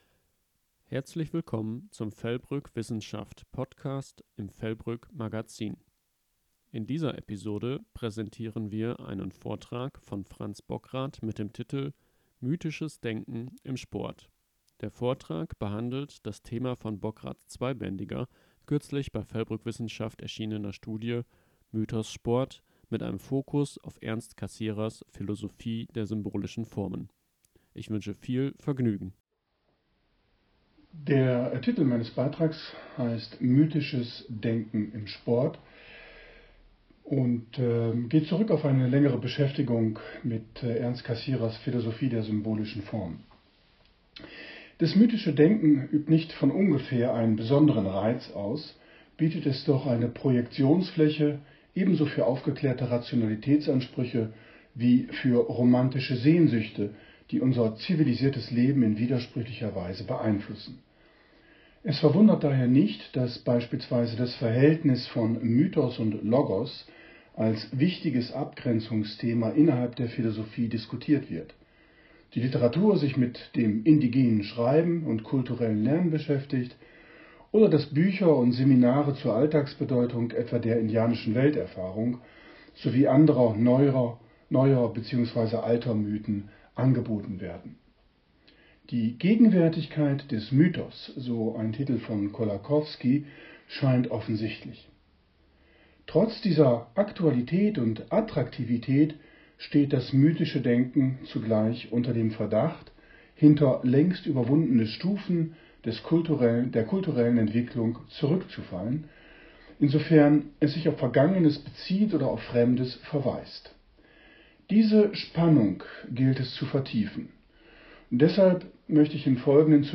Die neue Folge des VELBRÜCK WISSENSCHAFT PODCAST präsentiert den Mitschnitt eines Vortrags